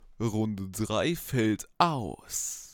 Flow: beste Runde Text: Bester Text Soundqualität: besser als ASMR Allgemeines: das war deine beste …